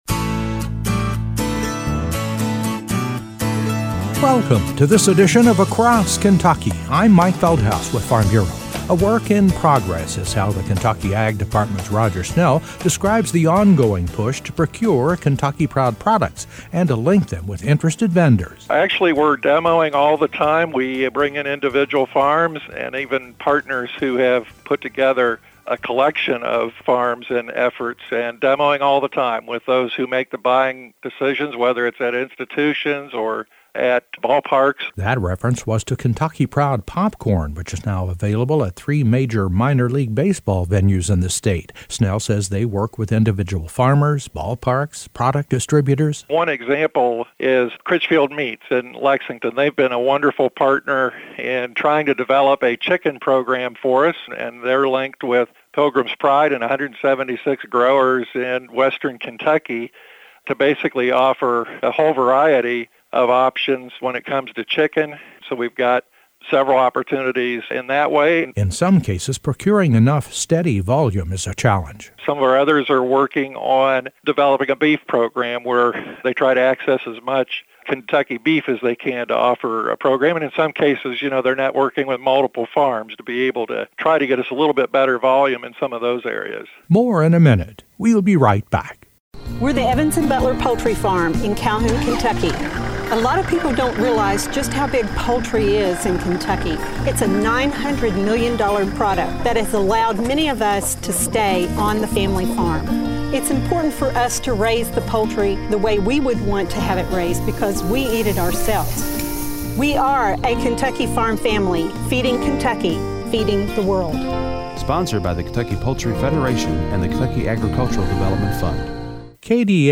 Across KentuckyA follow-up report today on the growth of Kentucky Proud products finding their way into more and more commercial venues in the state.